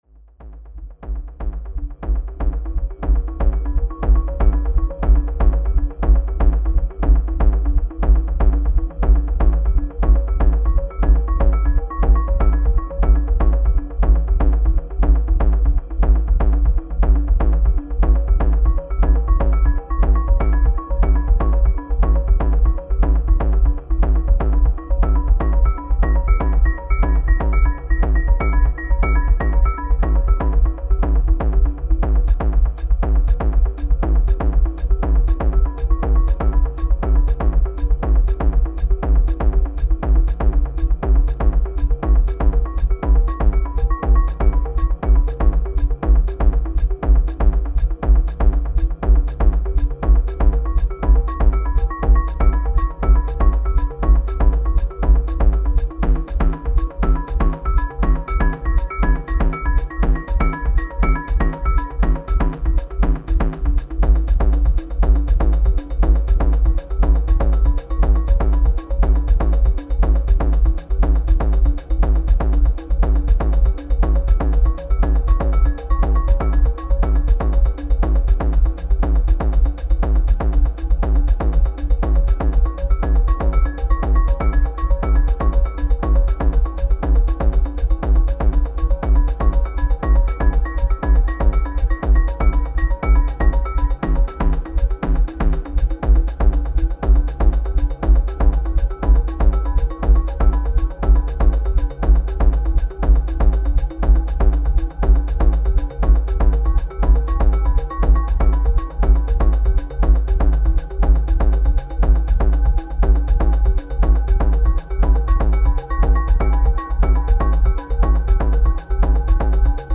Techno (испанский звук) мультитрек на опыты
совсем простенького, всё на стандартных инструментах Cubase 5